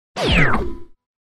Звуки выключения
Выключение системы